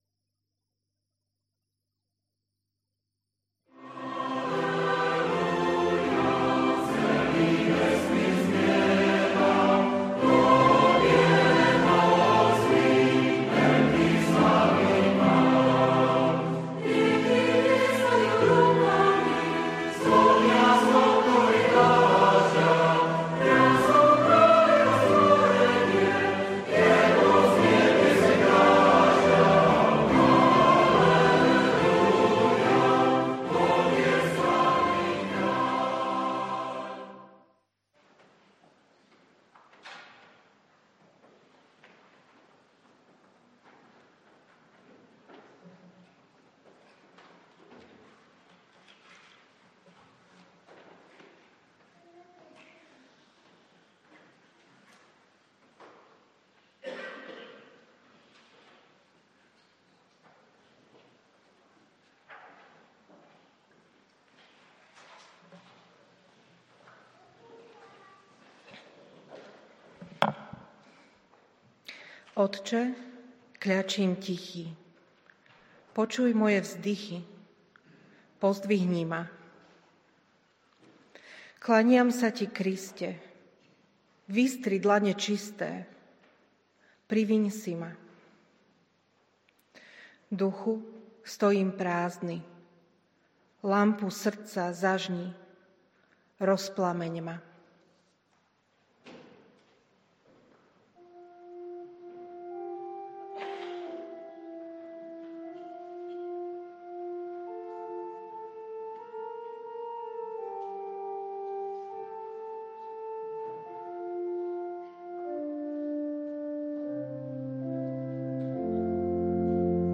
Mt 2:1-12 Podrobnosti Kázeň Prehliadač nepodporuje prehrávač.